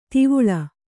♪ tivuḷa